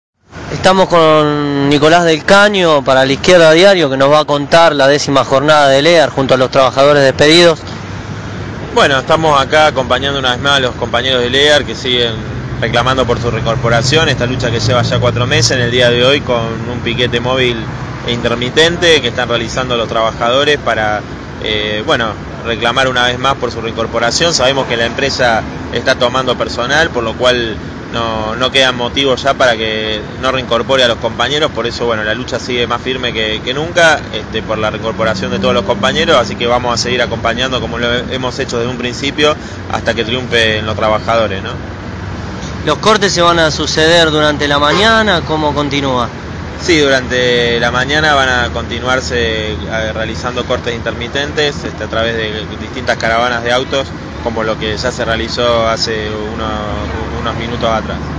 El diputado nacional Nicolás del Caño (PTS-FIT) habló con La Izquierda Diario desde el las puertas de la multinacional Lear, en la Panamericana. Del Caño participa de la Décima Jornada de Lucha contra los despidos en Lear.